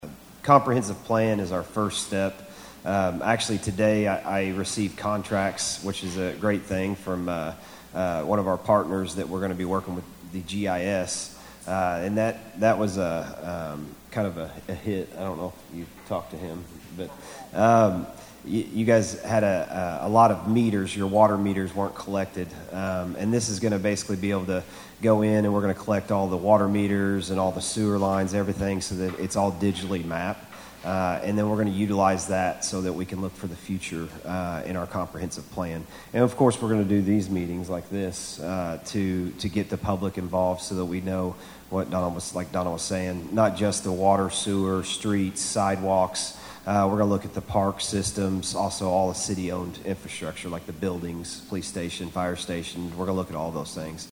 A Townhall Meeting was held Tuesday at Thayer High School